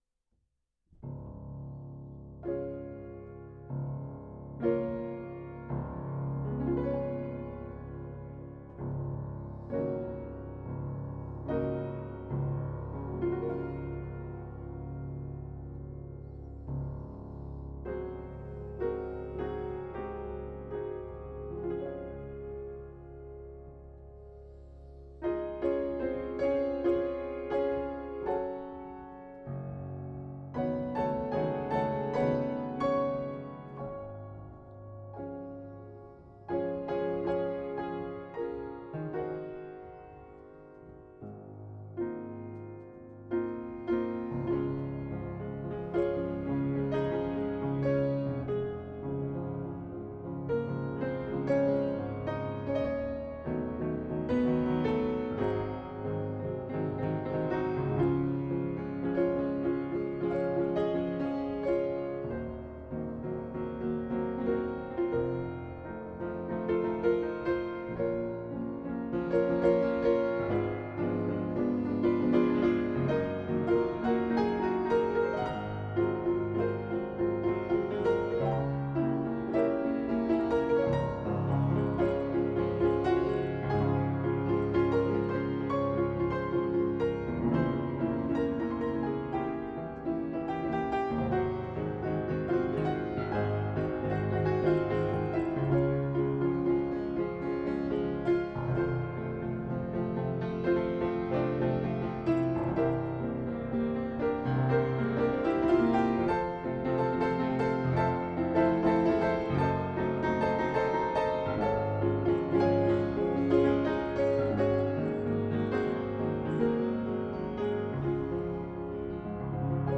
weird thing is my 88 sounds fine here apart from the odd note whereas the one the site swallowed up early today was definitely questionable tuning